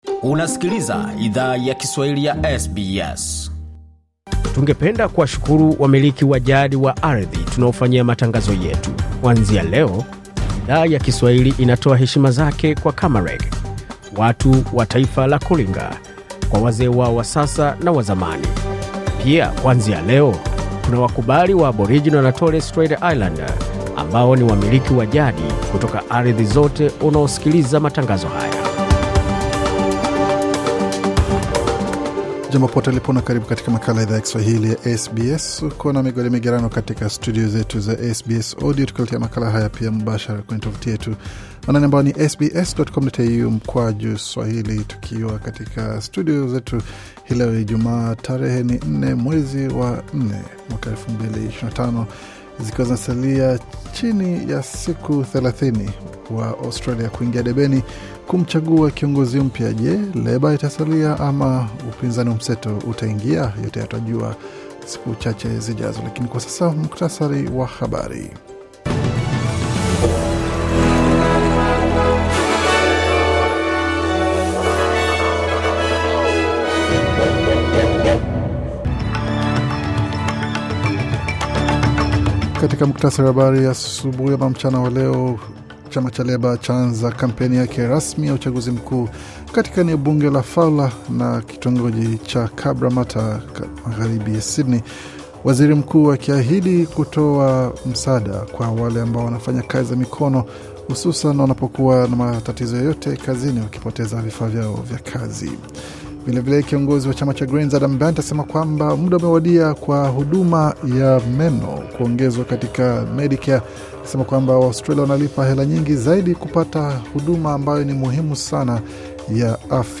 Taarifa ya Habari 4 Aprili 2025